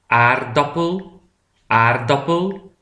de aardappel PRONONCIATION
aardappel.mp3